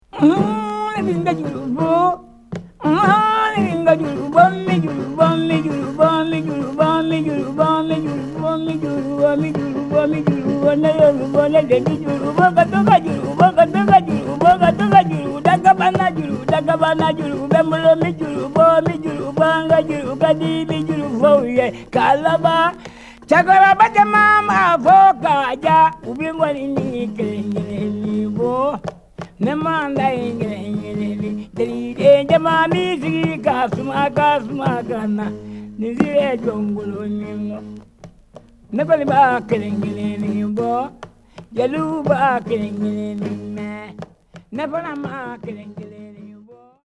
USED LP Mono